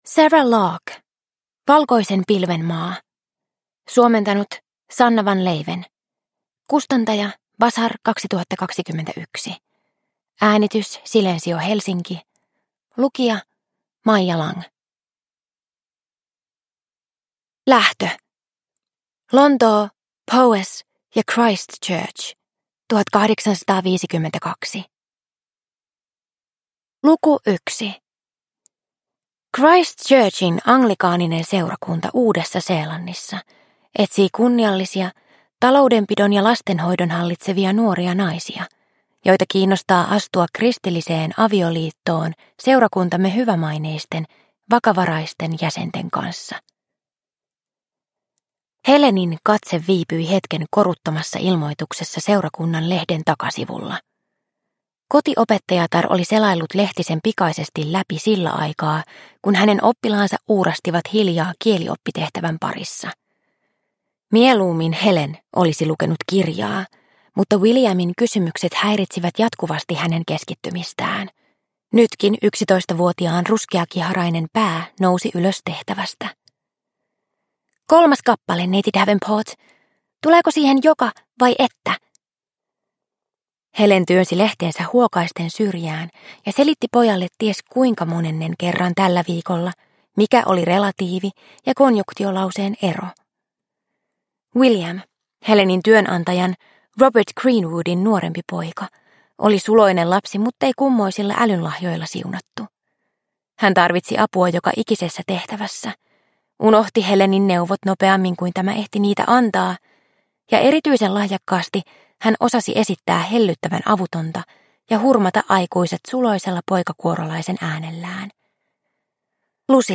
Valkoisen pilven maa – Ljudbok – Laddas ner